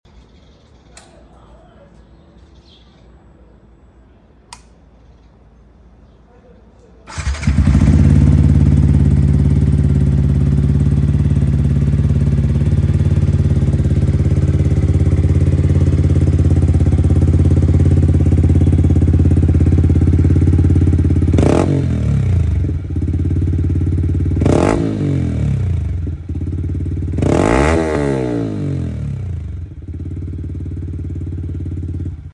Ronco Da Yamaha YZF R15 Sound Effects Free Download
Ronco da Yamaha YZF R15